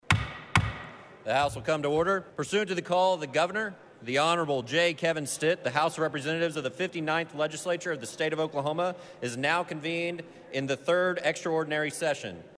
Charles McCall calls the special session of the state legislature into order
CLICK HERE to listen to House Speaker McCall call the special session into order.